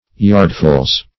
Search Result for " yardfuls" : The Collaborative International Dictionary of English v.0.48: Yardful \Yard"ful\, n.; pl. Yardfuls .
yardfuls.mp3